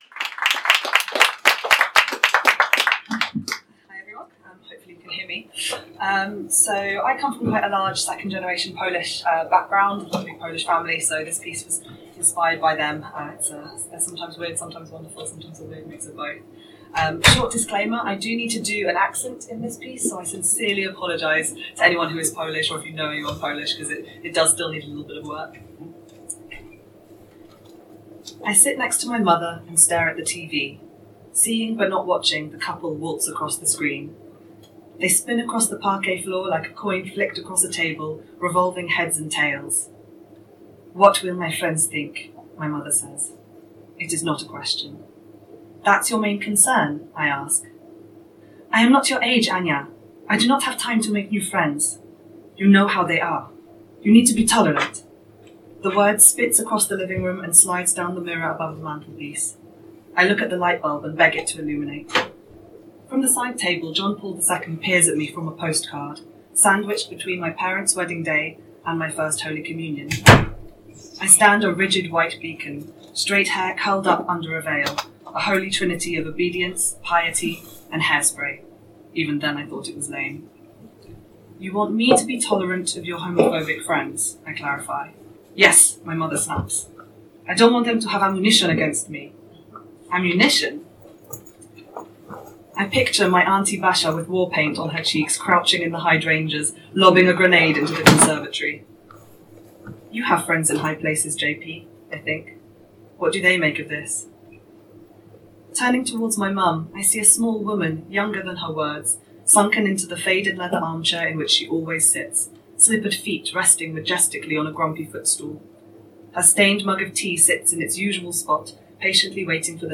First up on stage we were treated to a sensitively written piece, set in Poland.